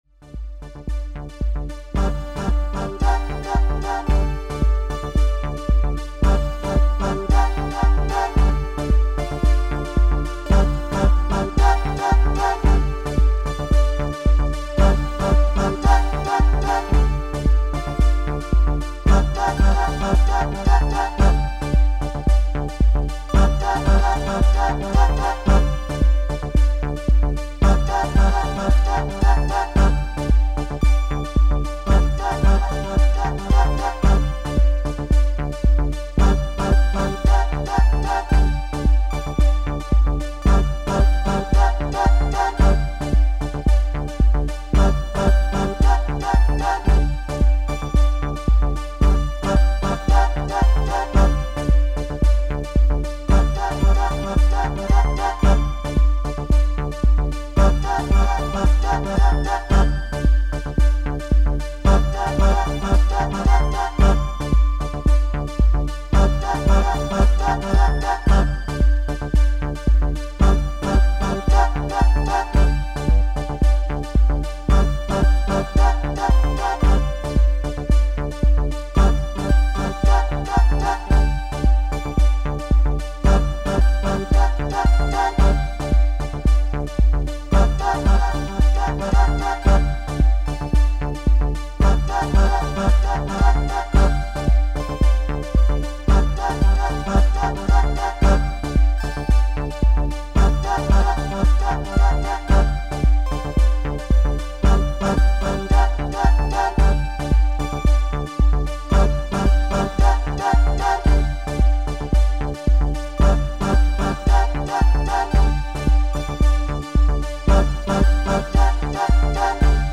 Tempo: 112 bpm / Datum: 29.01.2018